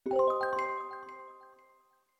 Old Music Box 1
bonus-sound film-production game-development intro magic music-box mystic mystical sound effect free sound royalty free Music